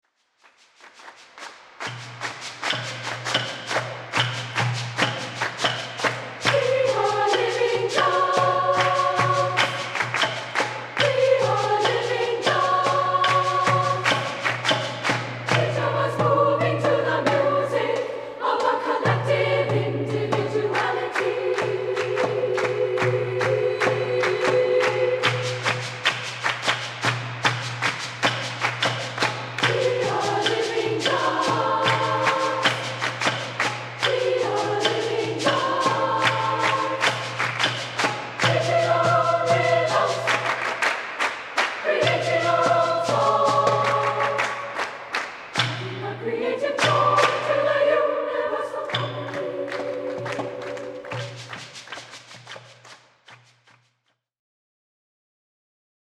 chorus (SSA) and percussion (opt.)